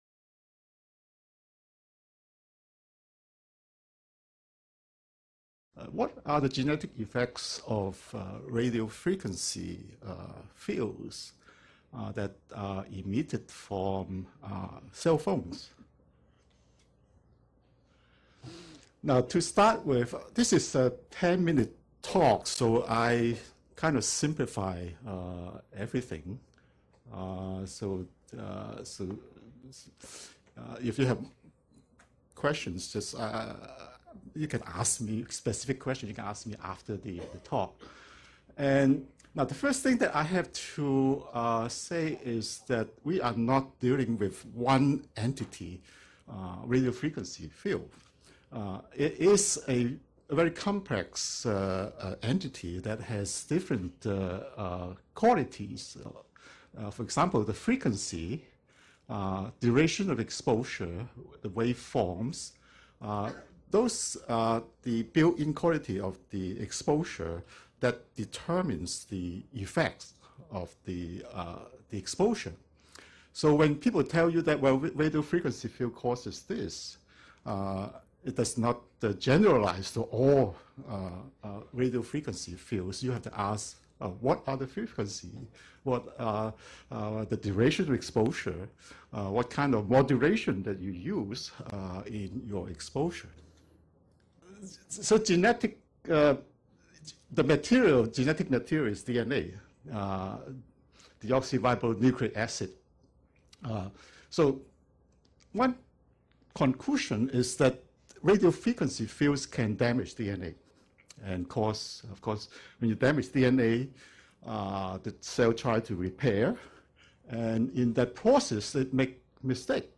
gives a talk on the genetic effects of cell phones and Radiofrequency radiation.